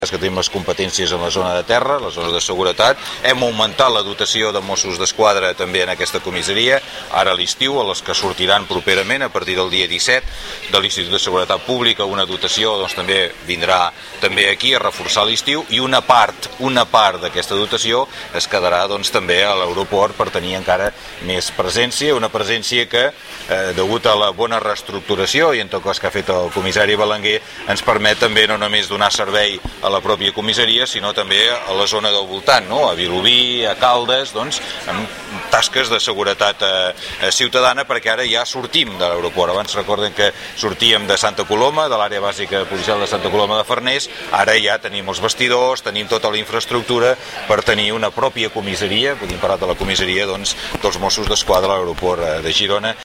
TALL DE VEU 2